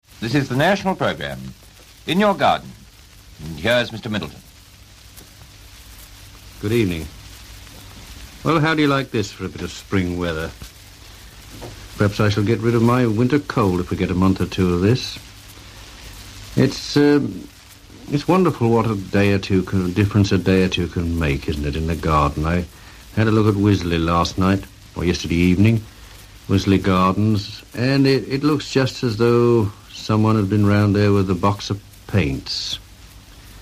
C.H. Middleton, the son of a Northamptonshire gardener spoke for fifteen minutes from notes alone on 'In Your Garden'.